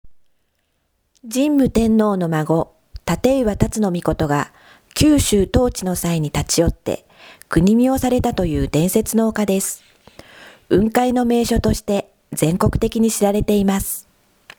音声でのご案内 (音声ファイル: 228.2KB) 神武天皇の孫・建磐龍命（たけいわたつのみこと）が九州統治の際に立ち寄って、国見をされたという伝説の丘。